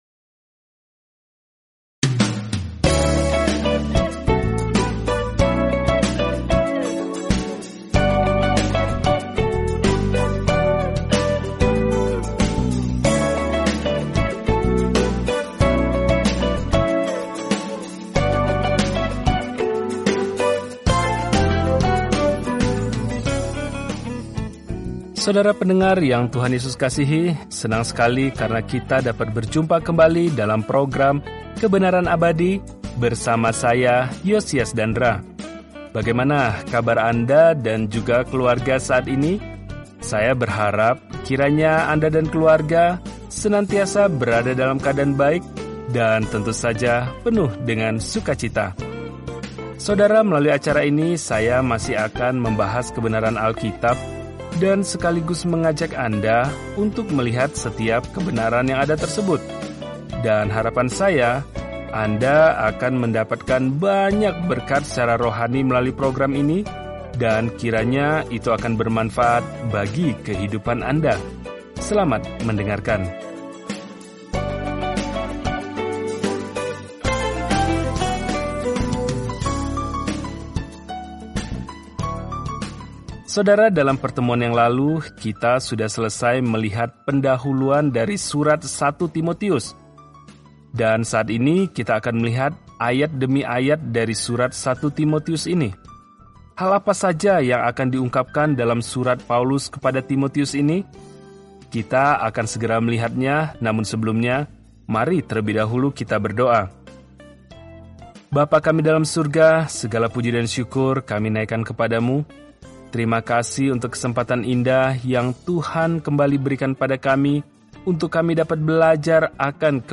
Firman Tuhan, Alkitab 1 Timotius 1:1-2 Hari 1 Mulai Rencana ini Hari 3 Tentang Rencana ini Surat pertama kepada Timotius memberikan indikasi praktis bahwa seseorang telah diubah oleh Injil – tanda-tanda kesalehan yang sejati. Telusuri 1 Timotius setiap hari sambil mendengarkan pelajaran audio dan membaca ayat-ayat tertentu dari firman Tuhan.